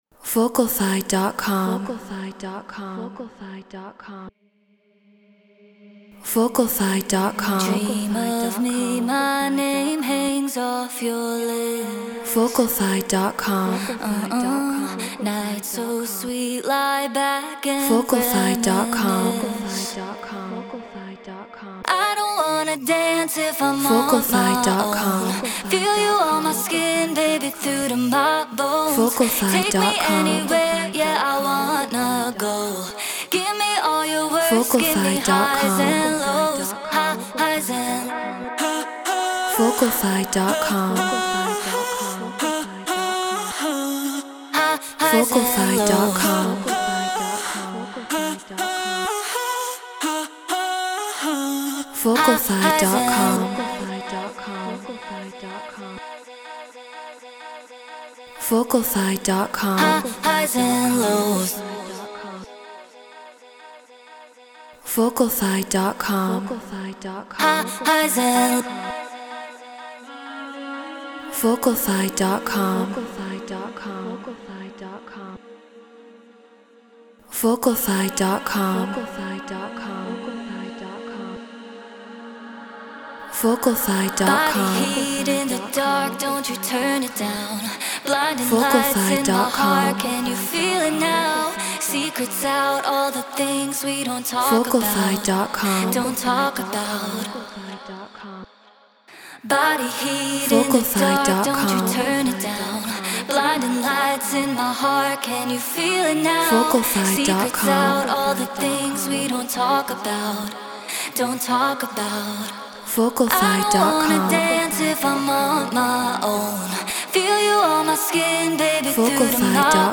House 126 BPM G#min
Treated Room